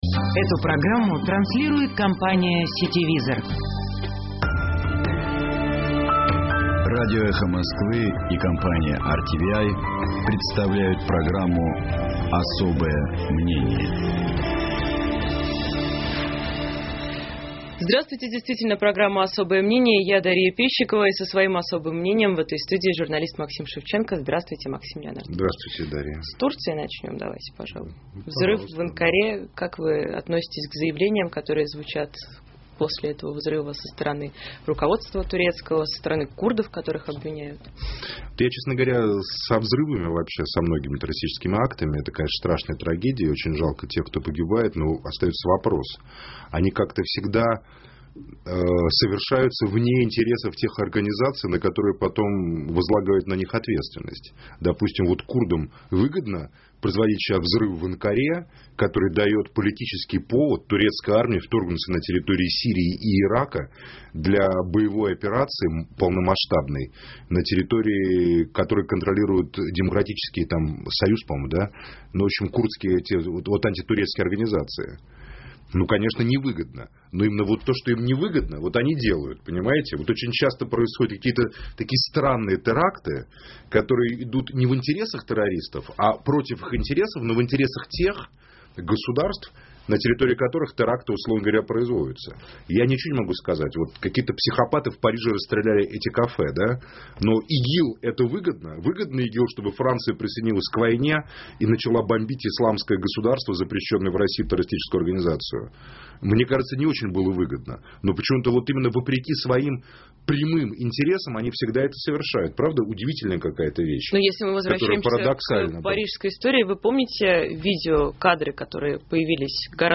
И со своим особым мнением в этой студии журналист Максим Шевченко.